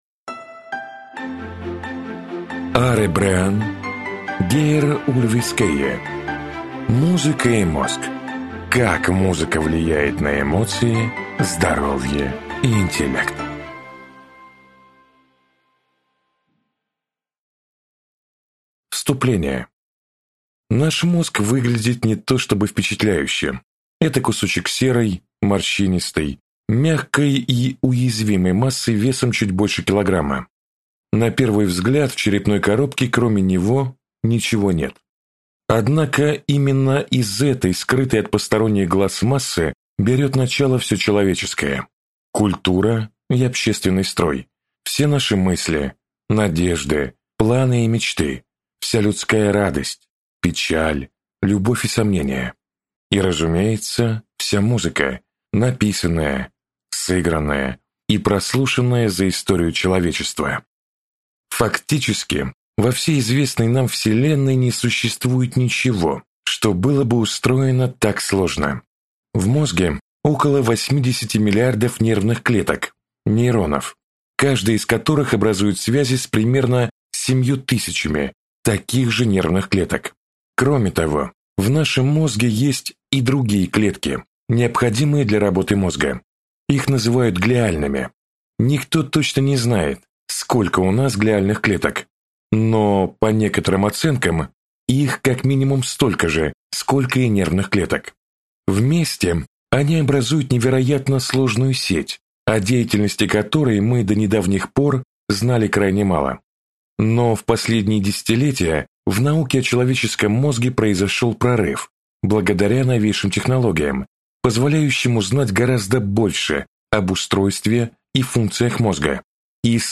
Аудиокнига Музыка и мозг | Библиотека аудиокниг